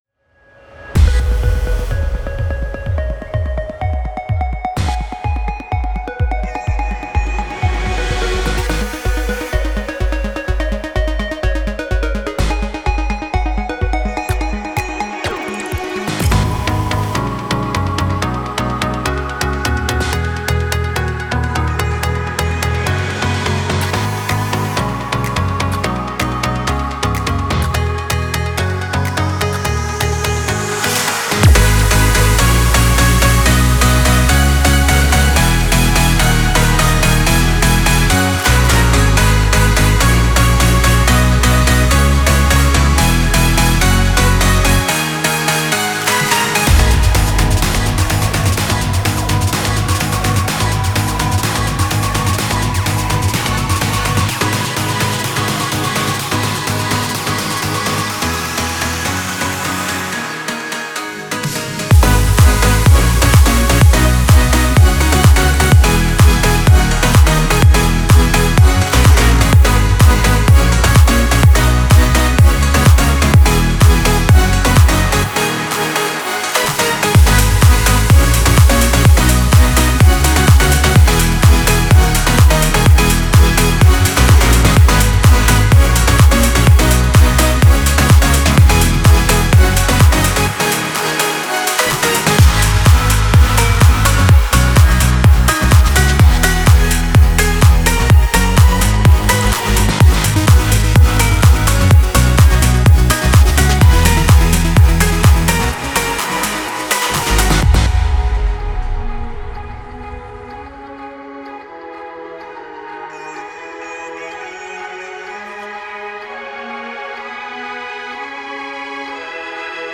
энергичная танцевальная композиция в жанре EDM